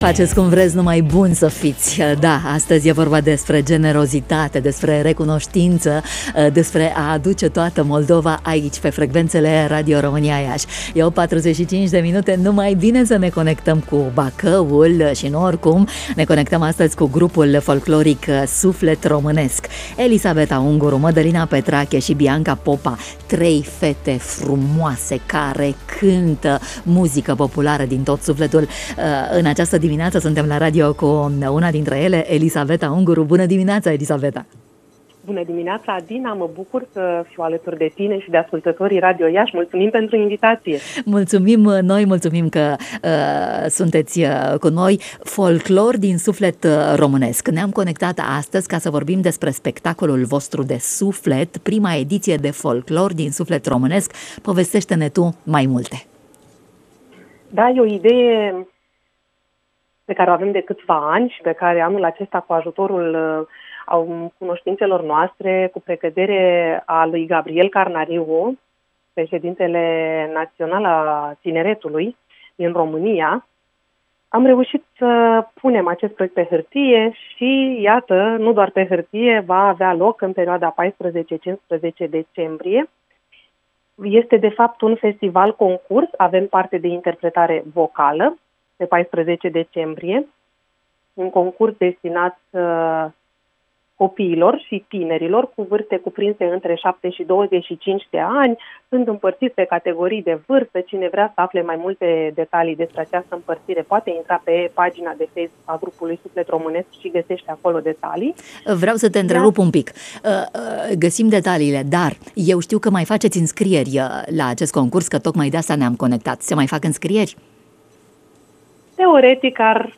organizator